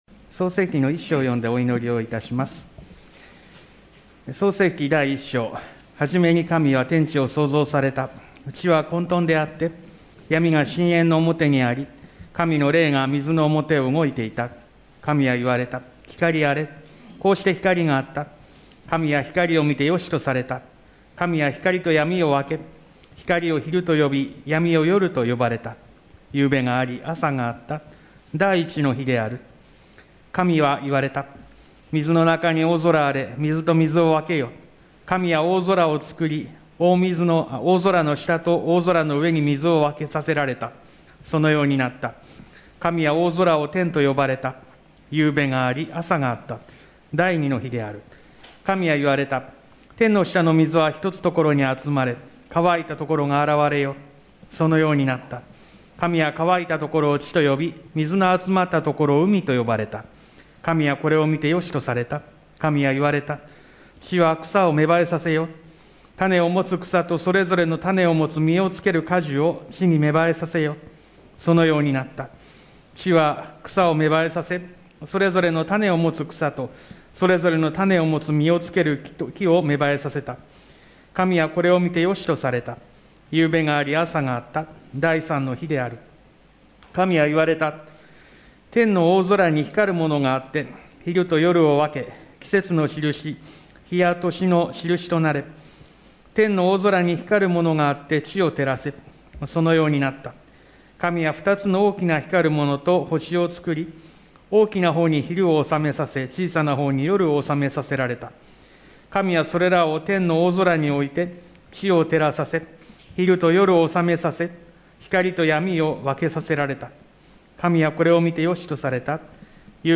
教会礼拝堂で、講壇用の大型聖書を用いて、リレー形式で聖書を声を出して読み進めます。
4月3日（日）礼拝後、聖書全巻リレー通読　開始の祈り
2022聖書全巻リレー通読開始の祈り音声　創世記　第1章の朗読と祈り